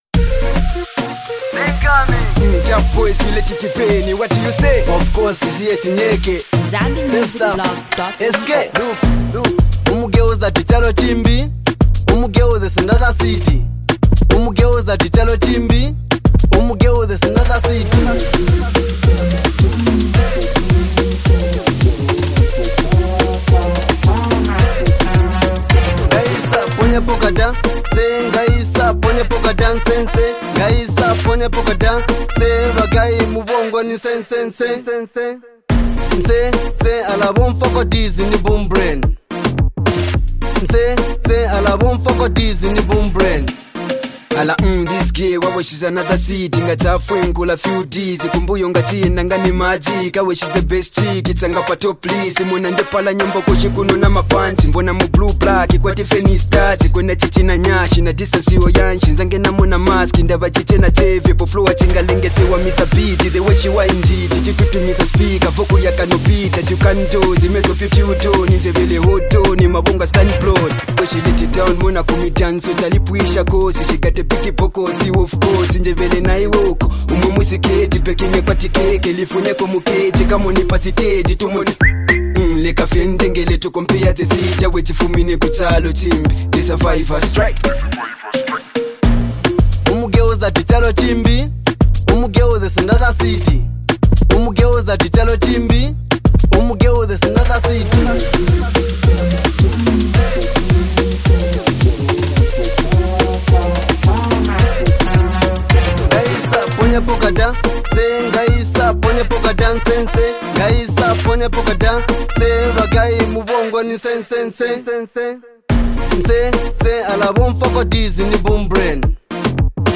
which is a perfect blend of dancehall music